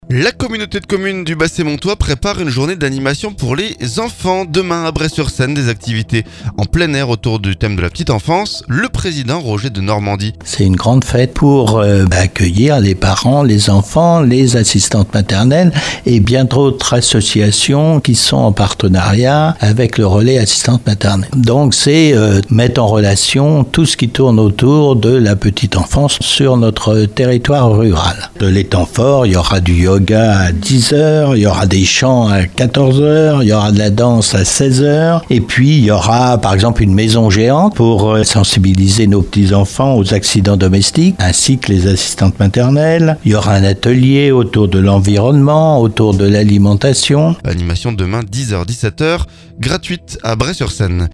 Différentes acivités sont prévues en plein air autour du thème de la petite enfance. Roger Denormandie, président de la Communauté de communes nous en dit plus.